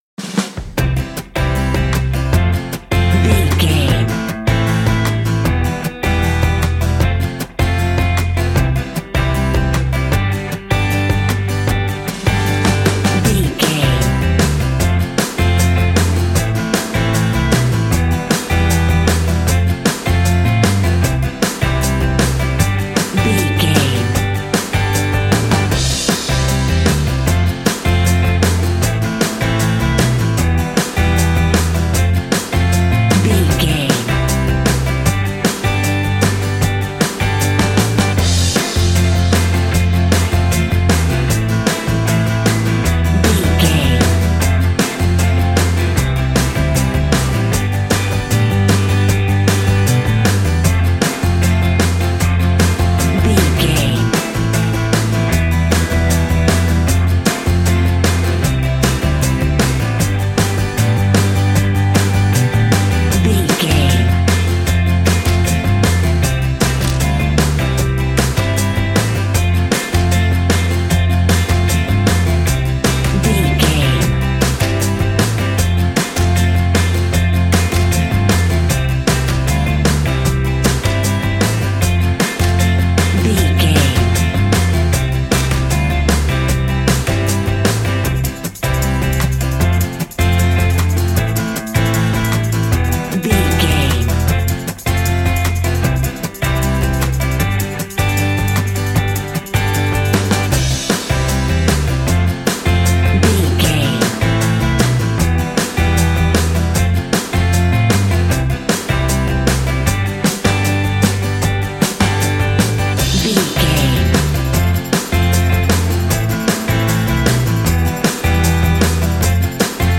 Ionian/Major
cool
uplifting
bass guitar
electric guitar
drums
cheerful/happy